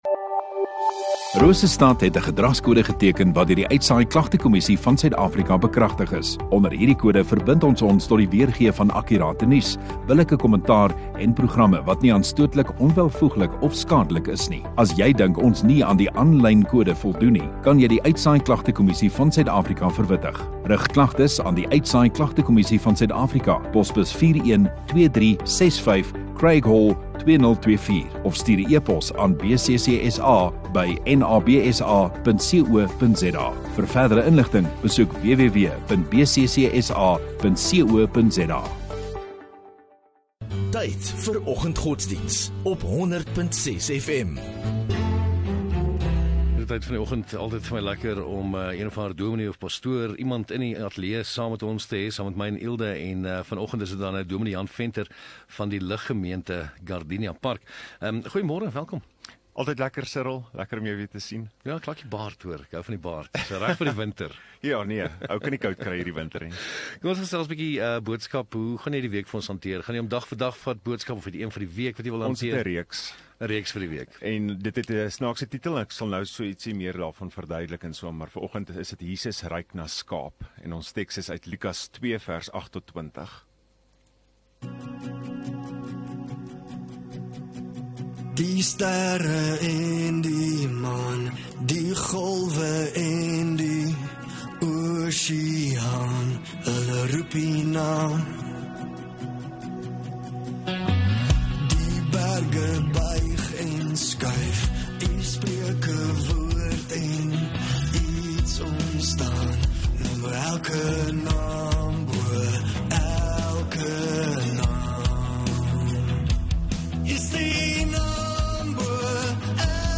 25 Mar Maandag Oggenddiens